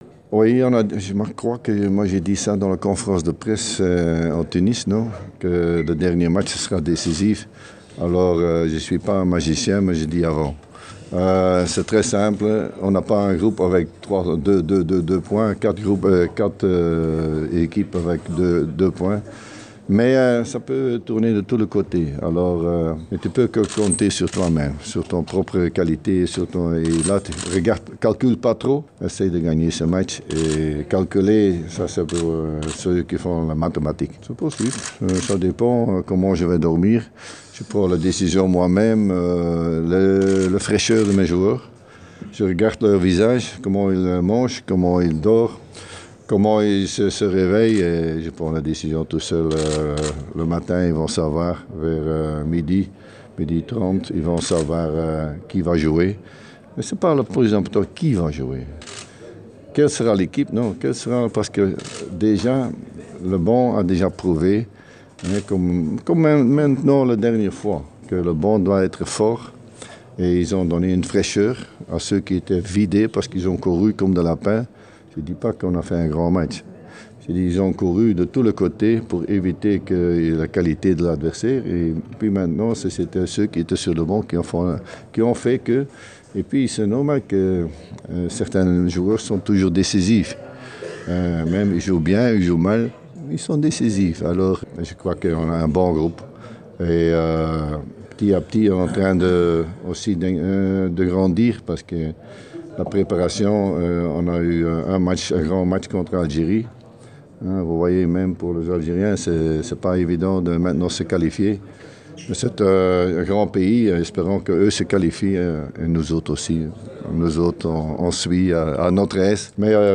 اكد الناخب الوطني للمنتخب التونسي خلال الندوة الصحفية التي عقدها اليوم في مقر اقامة المنتخب في مدينة "باطا"